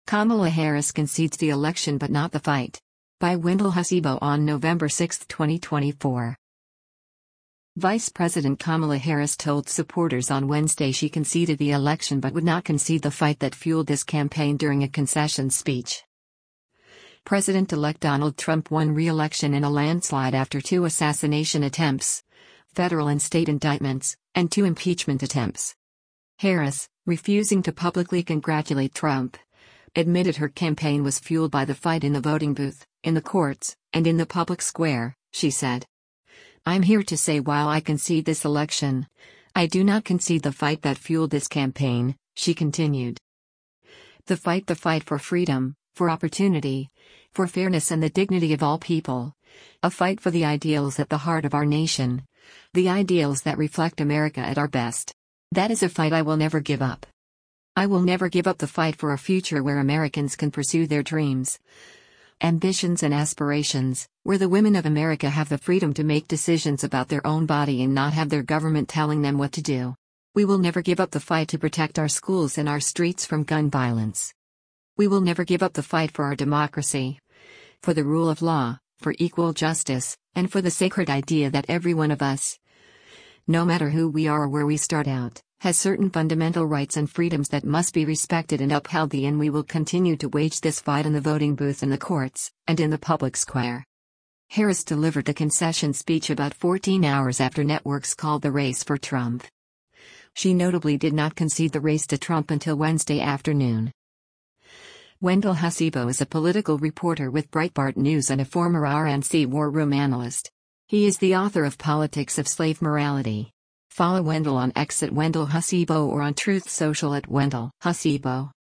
Vice President Kamala Harris delivers a concession speech after the 2024 presidential elec